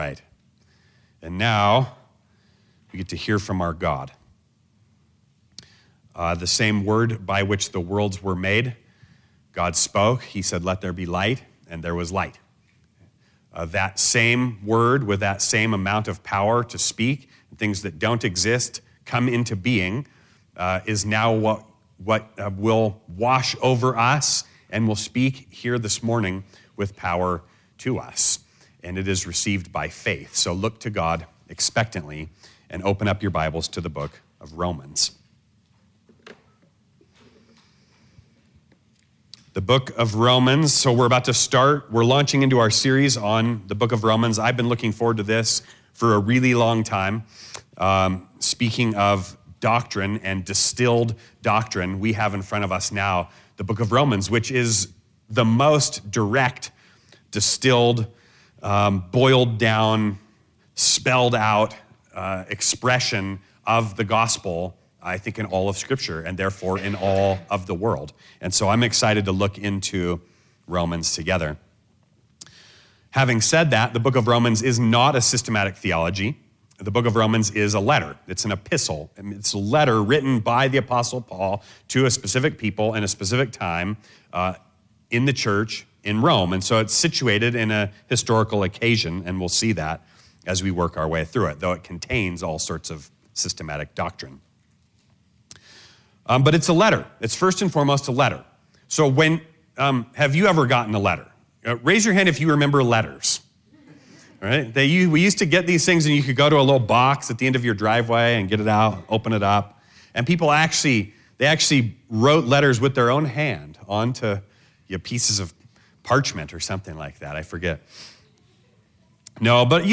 Reading Of Romans
Location: Gospel Church Durango